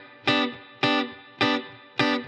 DD_TeleChop_105-Gmaj.wav